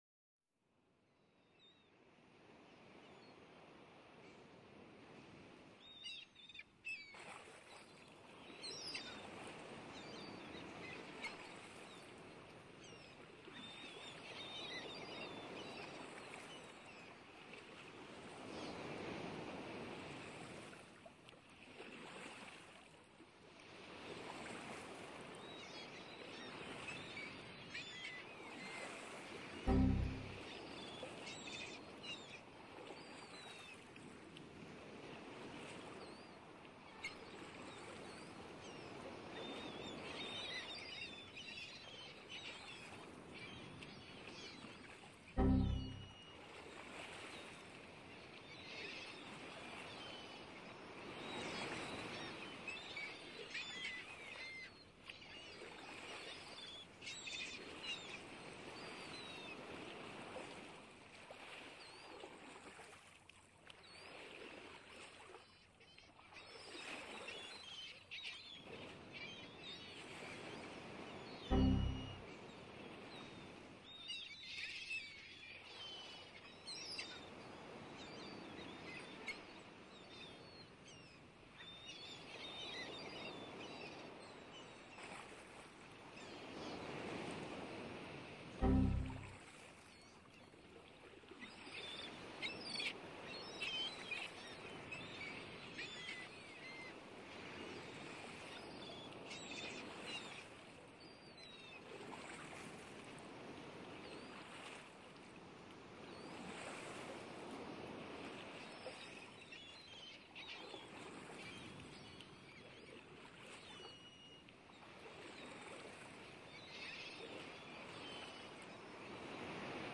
Czyste, naturalne d�wi�ki zgrywane nad brzegiem polskiego Ba�tyku.
Szum fal urozmaicony �piewem mew i innymi naturalnymi odg�osami powoduje naturalne wra�enie obecno�ci nad morzem.
Materia� d�wi�kowy zosta� zrealizowany z wielogodzinnych sesji w najpi�kniejszych miejscach polskiego Ba�tyku.